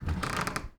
door_A_creak_03.wav